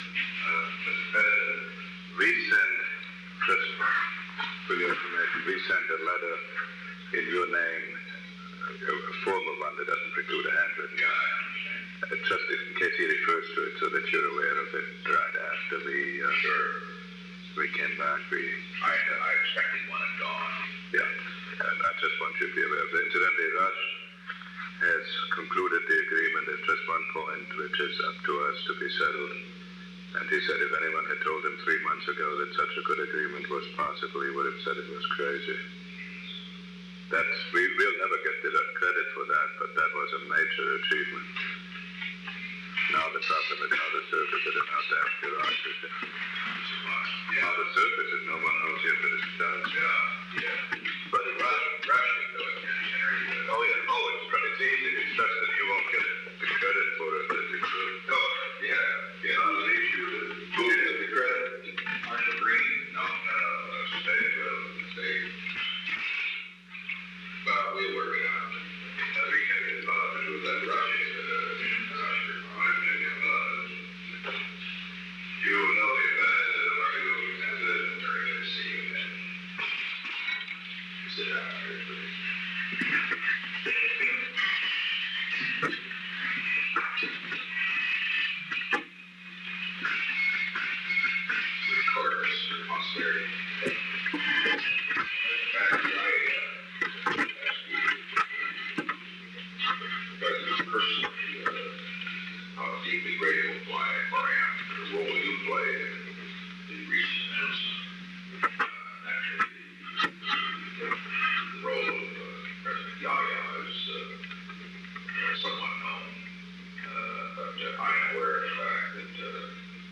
Conversation No. 551-6 Date: July 29, 1971 Time: 11:50 am - 12:20 pm Location: Oval Office The President met with Henry A. Kissinger.
Secret White House Tapes